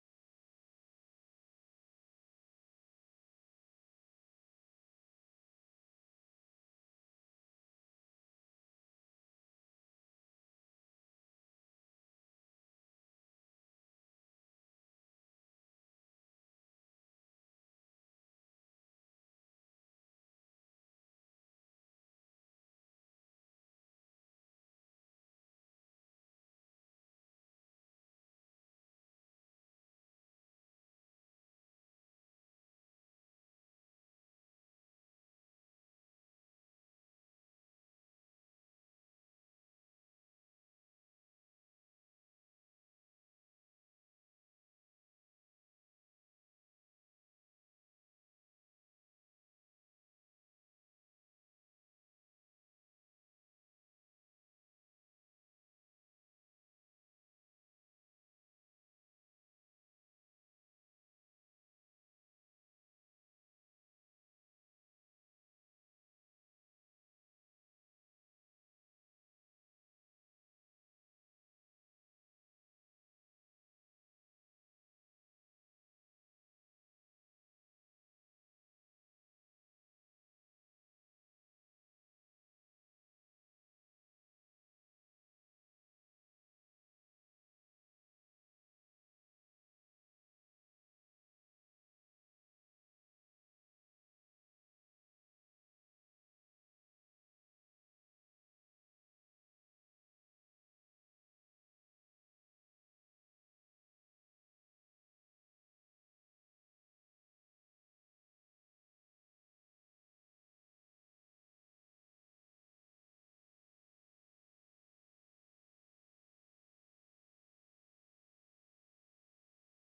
Praise and Worship on June 23rd 2024
Join us for our weekly service in-person or online starting at 9:45 A.M. every Sunday.